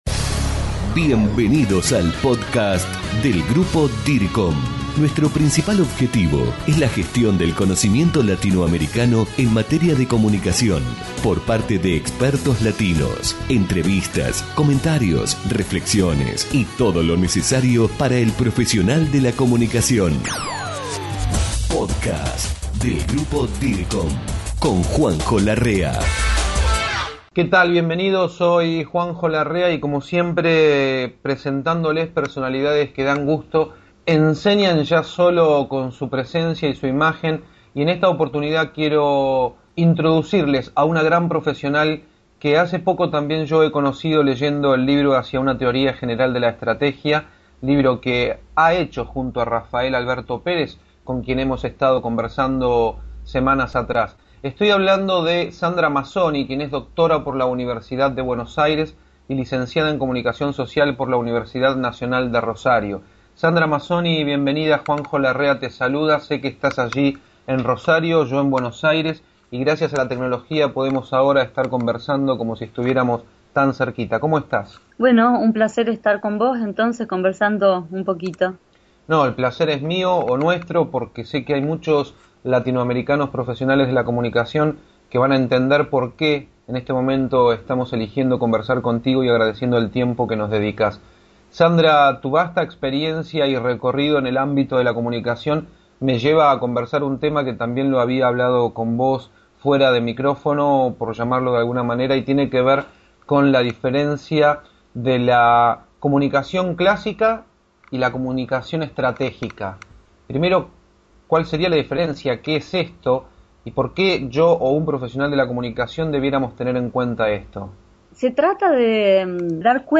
Lo que sigue es una conversación